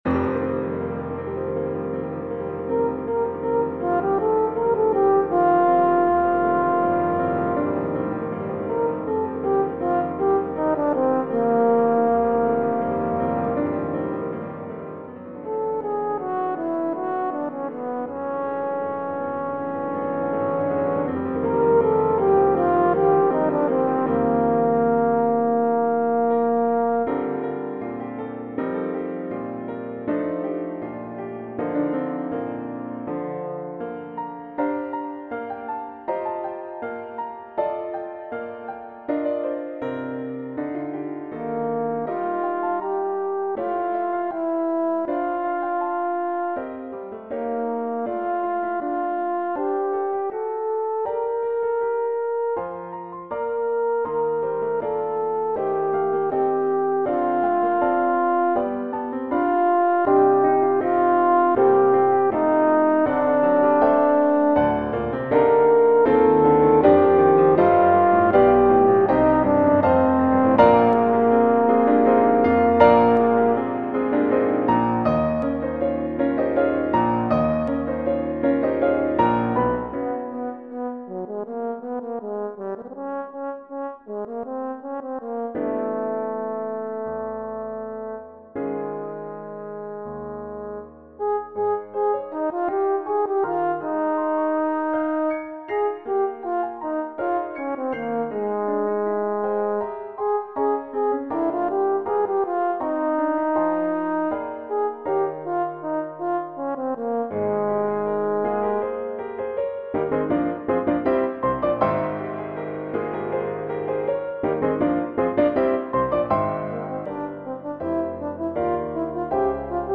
arranged for Horn in F and Piano.
Horn in F, Trombone